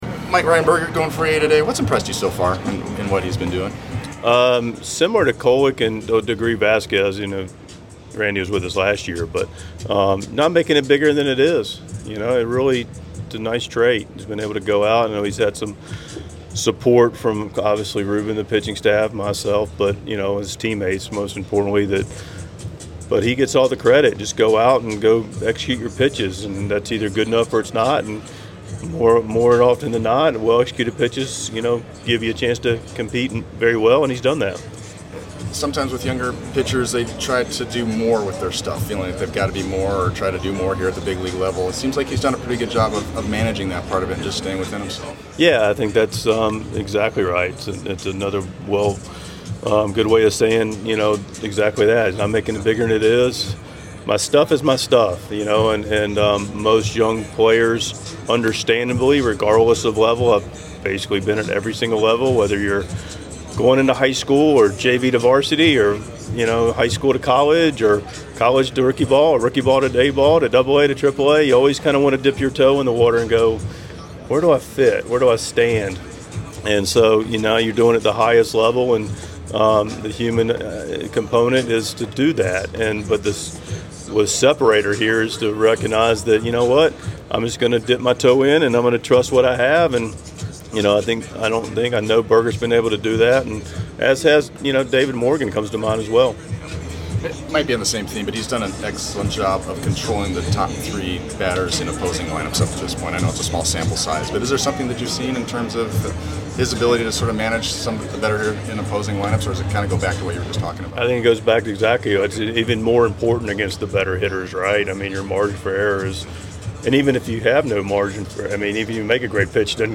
6.19.25 Mike Shildt Pregame Press Conference